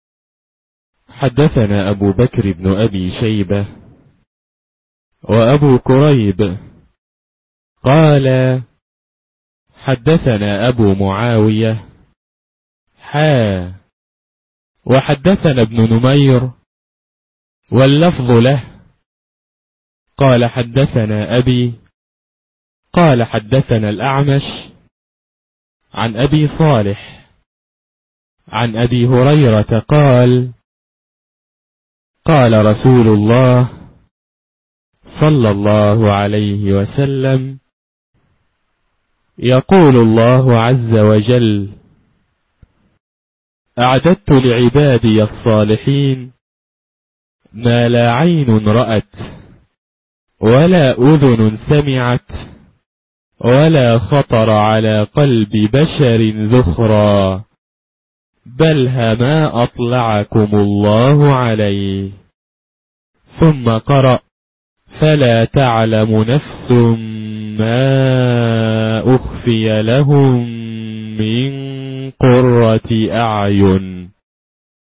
3. الـكتب الناطقة باللغة العربية